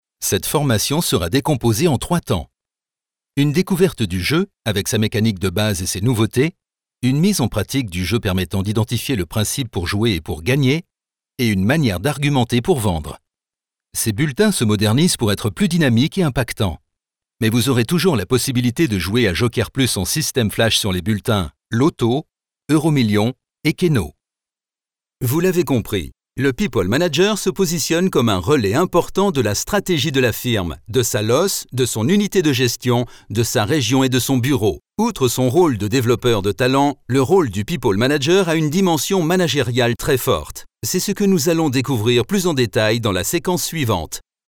Voix caméléon 3 octaves.
Comédien Français, voix grave médium caméléon.
Sprechprobe: eLearning (Muttersprache):